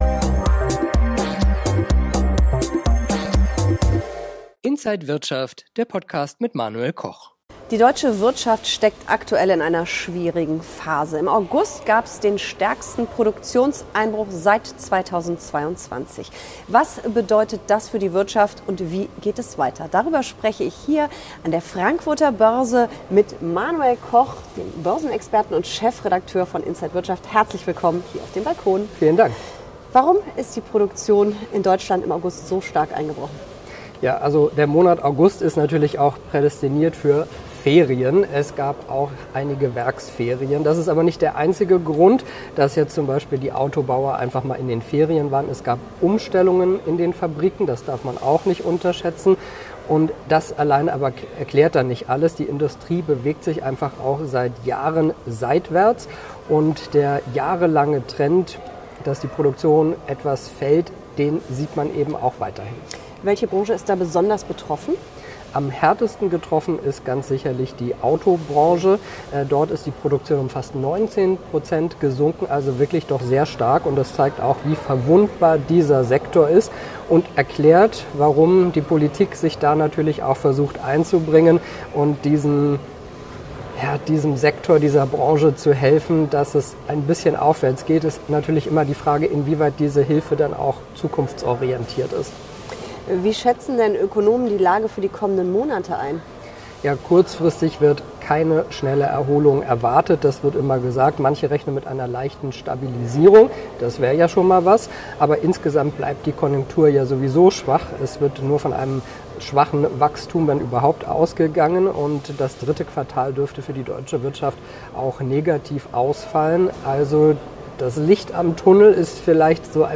Alle Details im Interview von
an der Frankfurter Börse